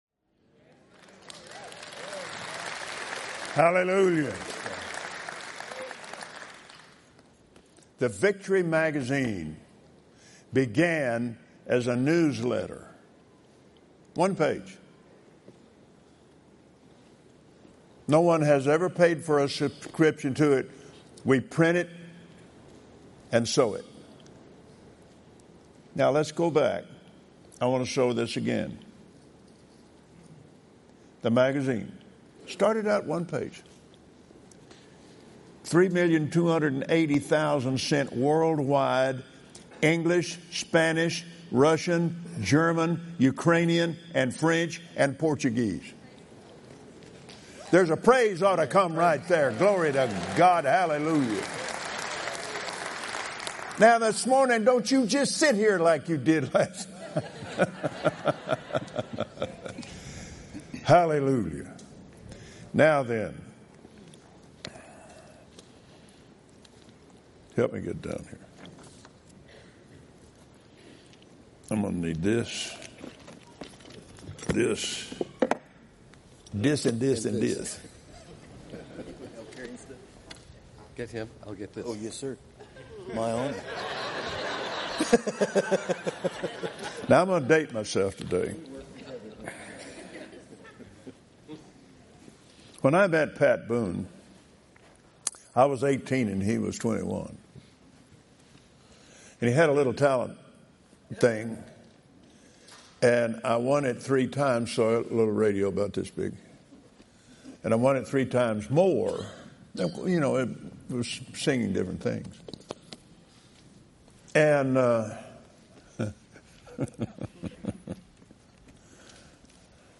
Listen as Brother Copeland tells how God is using Kenneth Copeland Ministries to BLESS the world through radio, television, Victory Channel, BVOV magazine, books, recordings, study materials, Kenneth Copeland Bible College, donations to support other ministries, gifts to the poor and many other ways.